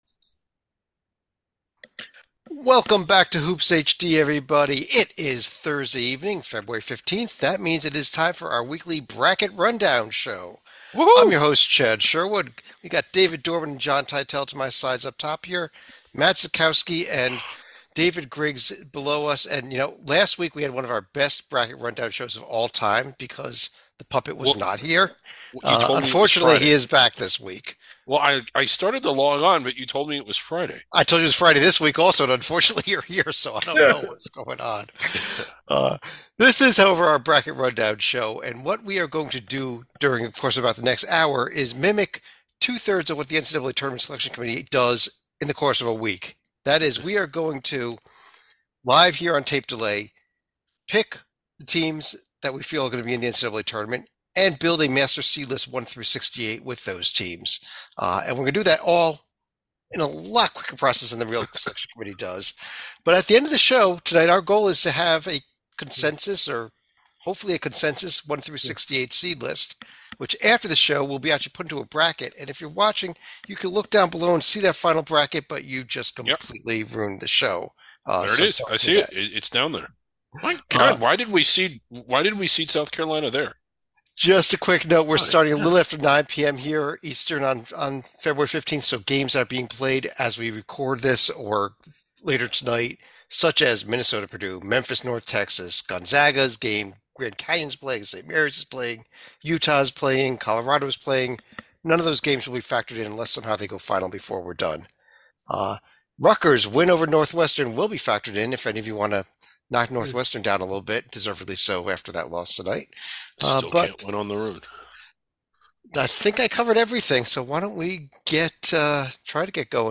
NOTE: This broadcast was recorded at 9pm, est on Thursday, February 15th.